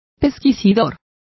Complete with pronunciation of the translation of investigators.